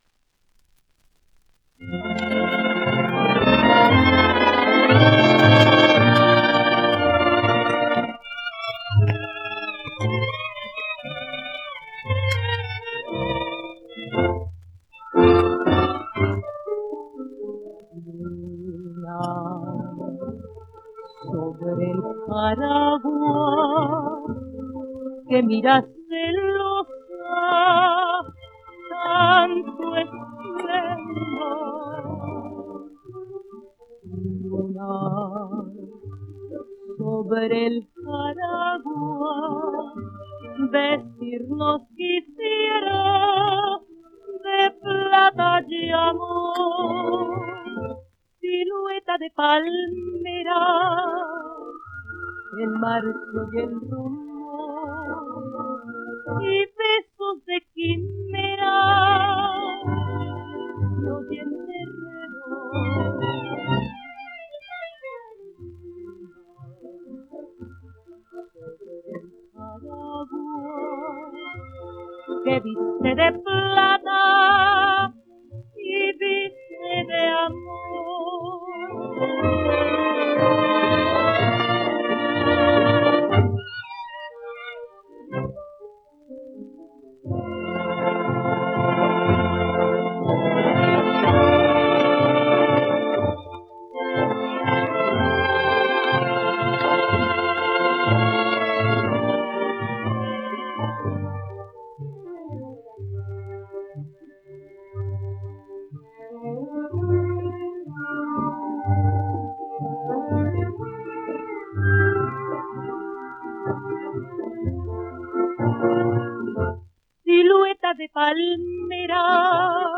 1 disco : 78 rpm ; 25 cm Intérprete